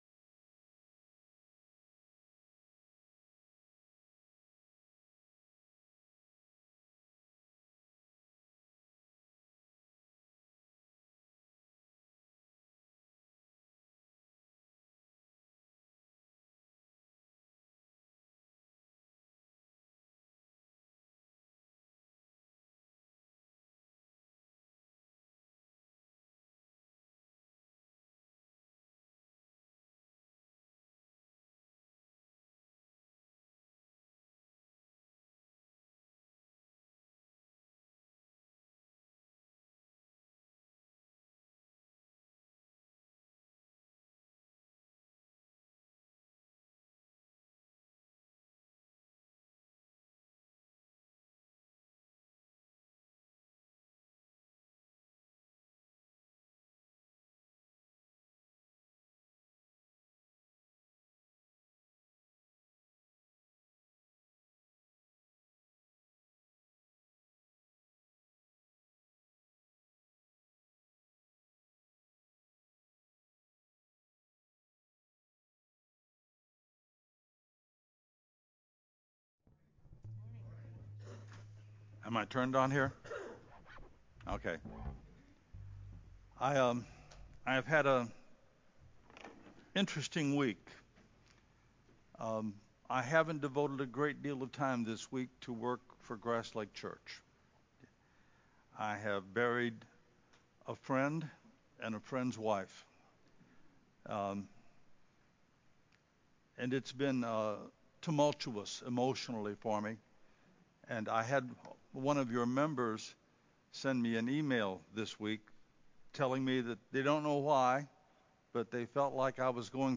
Mighty Man of Valor Sermon